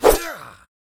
Sword_04.ogg